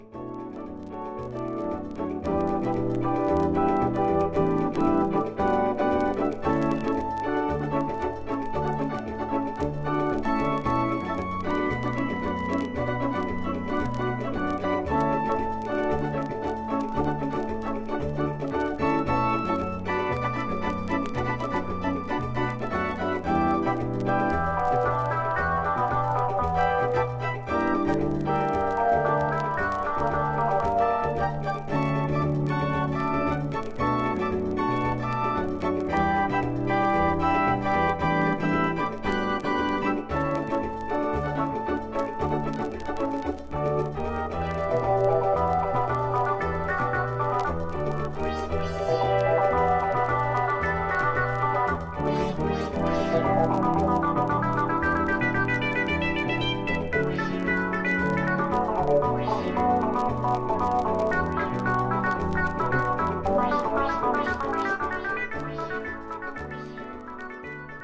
エレクトーン物中心、国内イージーリスニングLP20枚入荷しました。